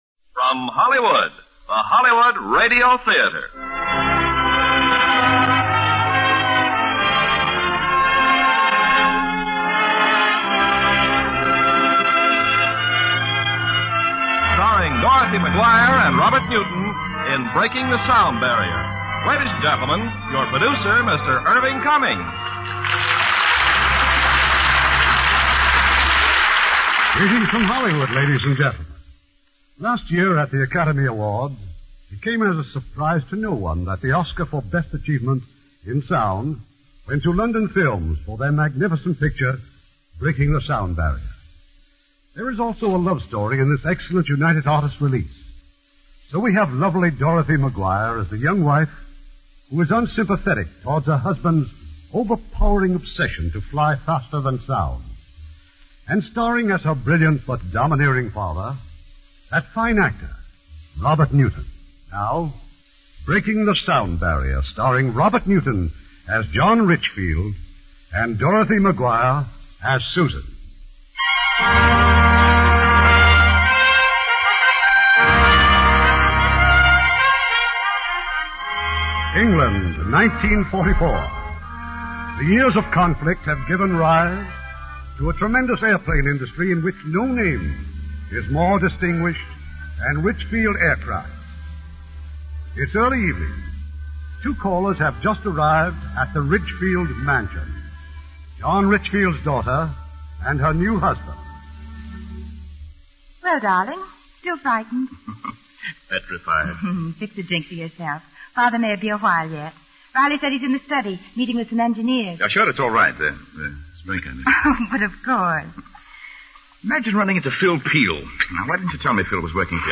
Breaking the Sound Barrier, starring Robert Newton, Dorothy McGuire